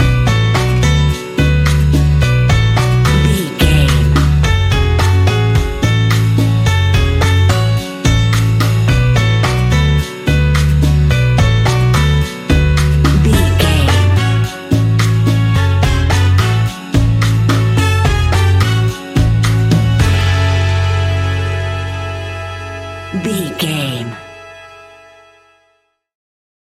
Aeolian/Minor
calypso
steelpan
drums
percussion
bass
brass
guitar